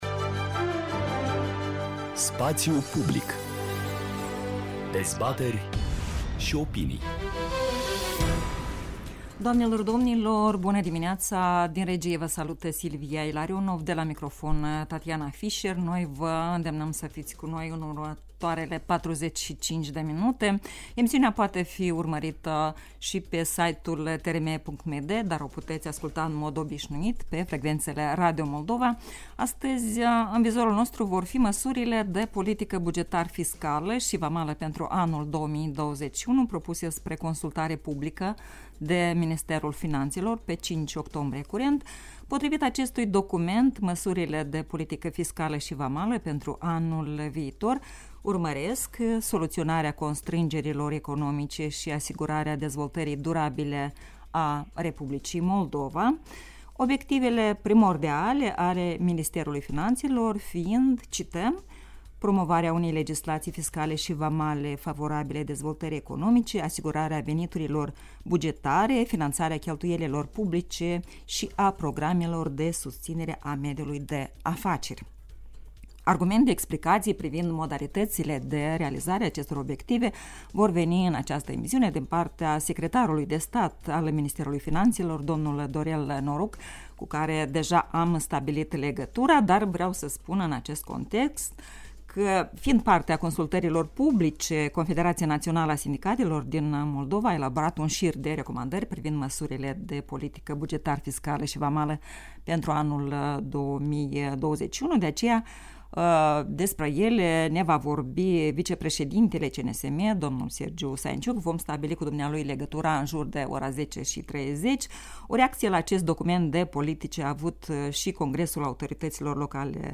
În cadrul unei emisiuni la Radio Moldova